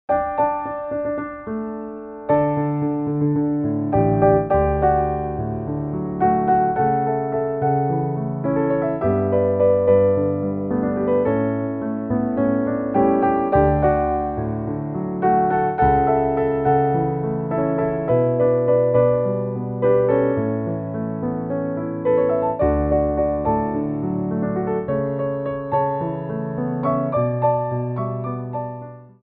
4/4 (8x8)